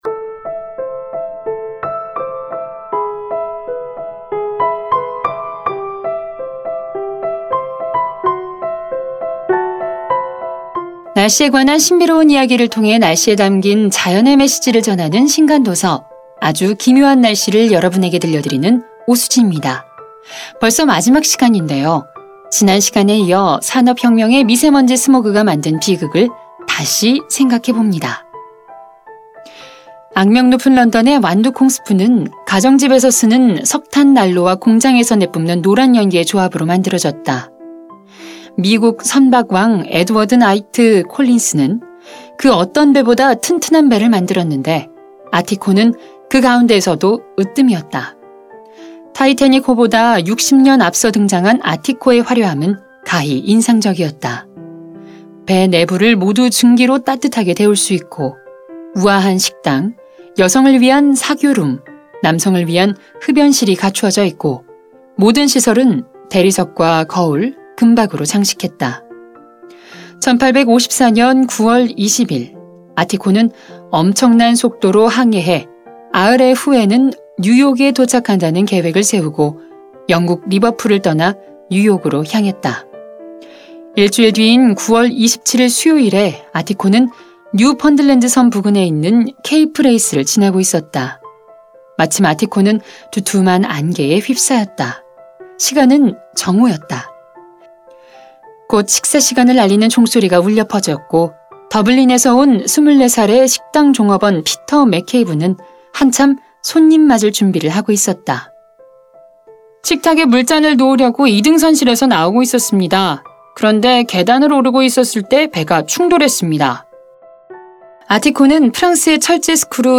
매거진 책 듣는 5분 ㅣ 오디오북